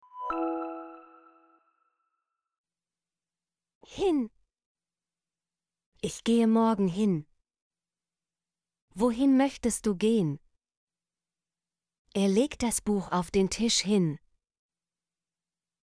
hin - Example Sentences & Pronunciation, German Frequency List